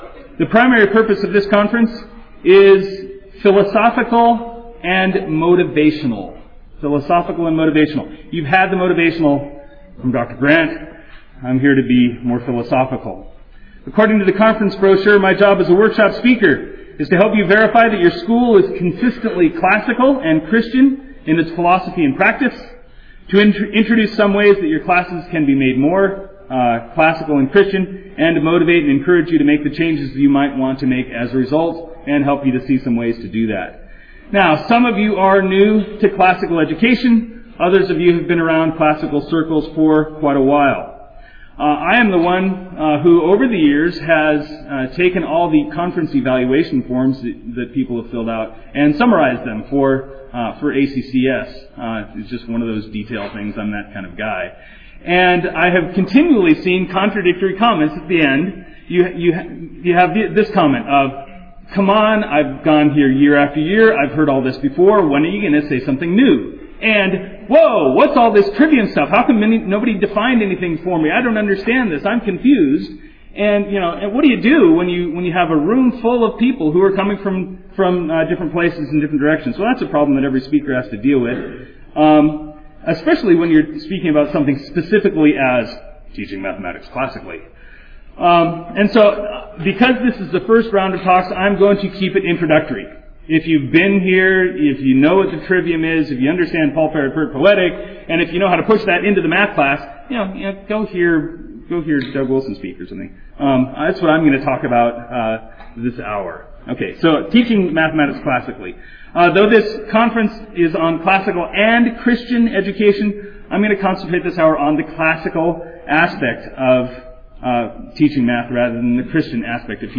2007 Workshop Talk | 0:53:50 | 7-12, Math
The Association of Classical & Christian Schools presents Repairing the Ruins, the ACCS annual conference, copyright ACCS.